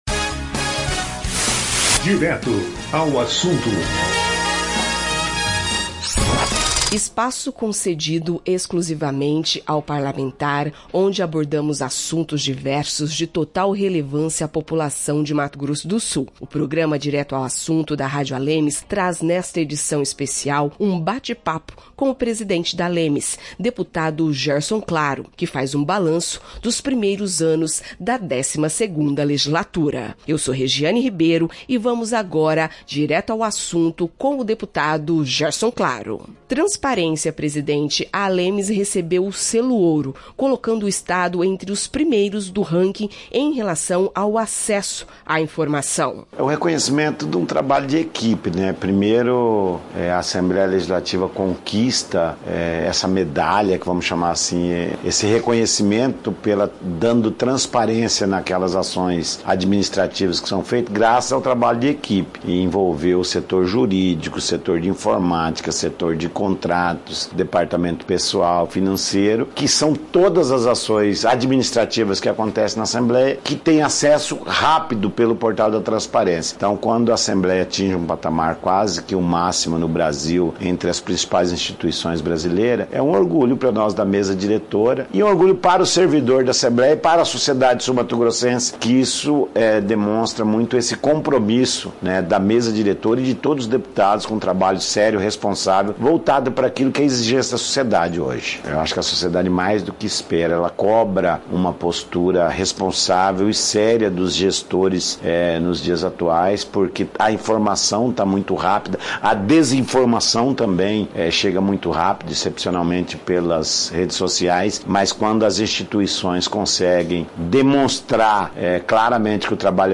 O presidente Assembleia Legislativa de Mato Grosso do Sul (ALEMS), deputado Gerson Claro (PP), em entrevista à Comunicação Institucional da Casa de Leis, fez um balanço dos trabalhos realizados durante biênio 2023-2024da 12ª legislatura. Dentre as novidades está um novo concurso para o quadro administrativo do Legislativo.